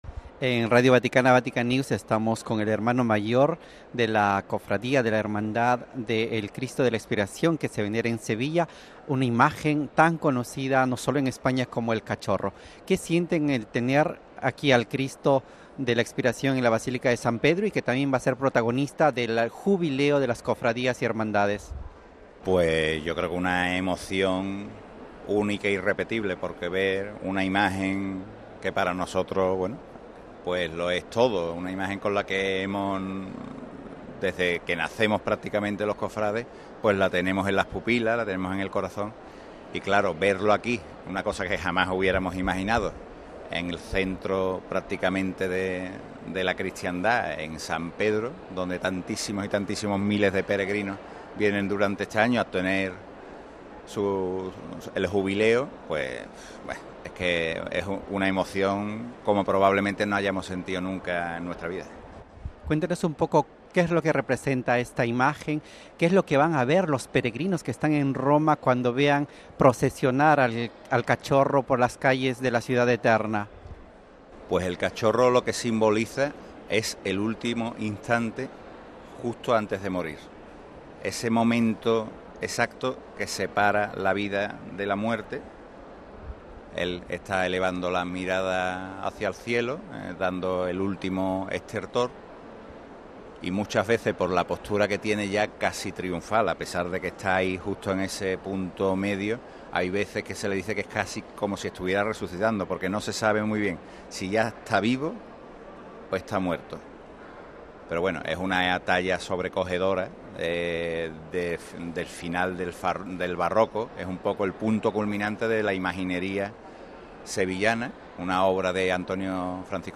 (Tagstotranslate) Religious Interview (T) Interview (T) Spain (T) Pilgrimage